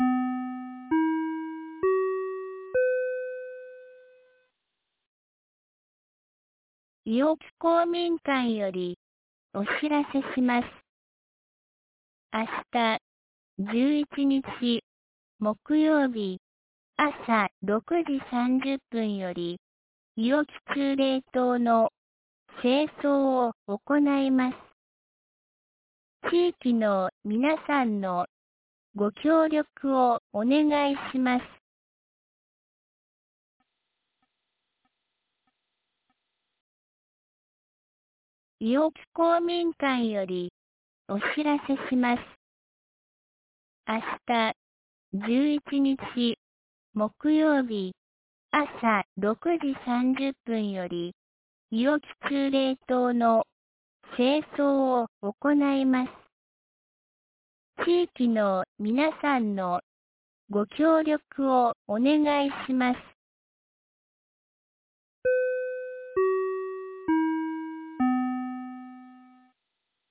2022年08月10日 17時11分に、安芸市より伊尾木、下山へ放送がありました。